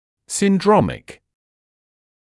[sɪn’drɔmɪk][син’дромик]синдромный